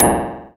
SI2 METALD0G.wav